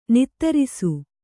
♪ nittarisu